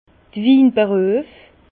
Bas Rhin d'r Winberüef
Prononciation 67 Herrlisheim